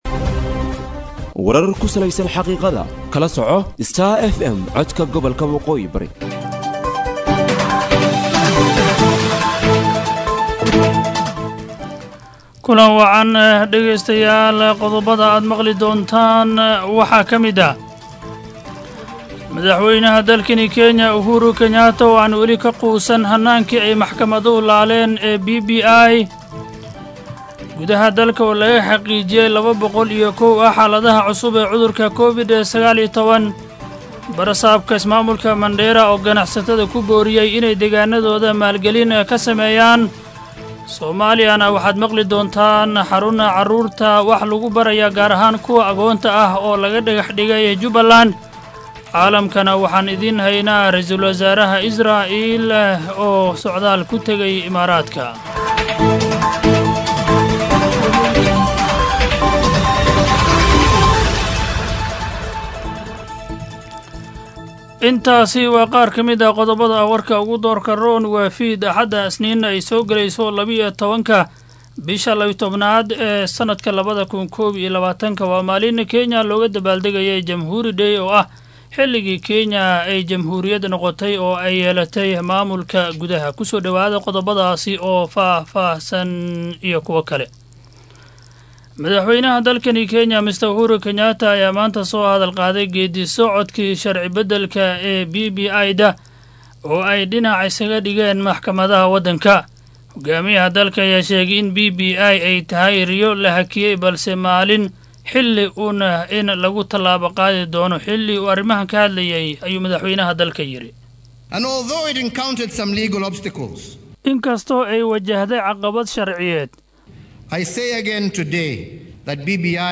DHAGEYSO:WARKA HABEENIMO EE IDAACADDA STAR FM